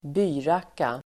Ladda ner uttalet
Uttal: [²b'y:rak:a]